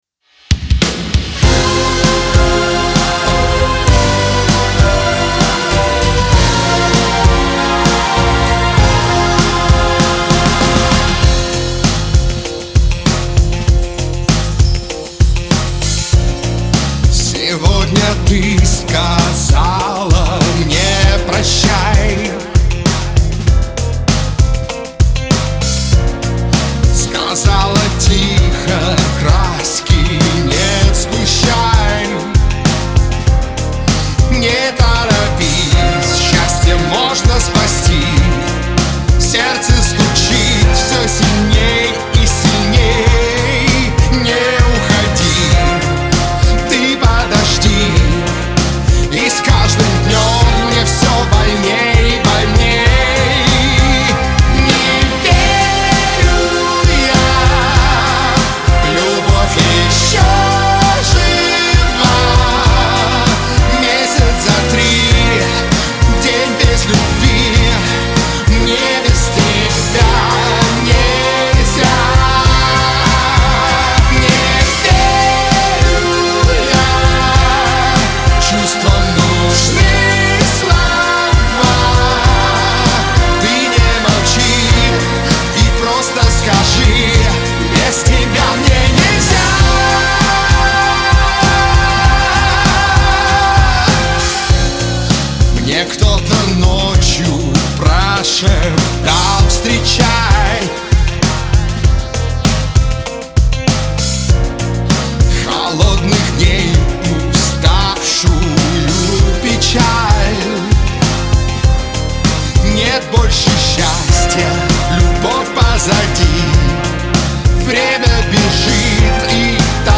Трек размещён в разделе Русские песни / Эстрада.